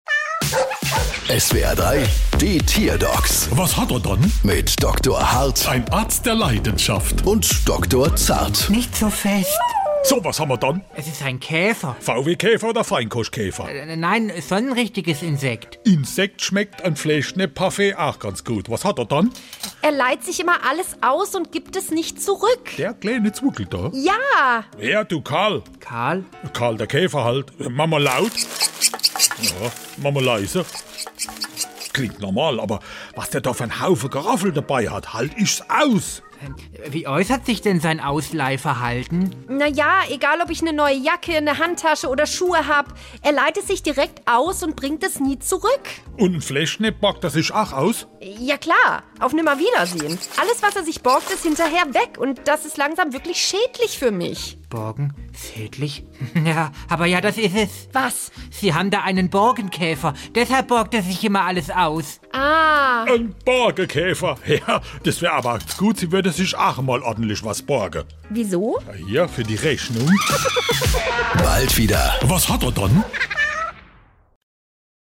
SWR3 Comedy Die Tierdocs: Käfer borgt sich alles aus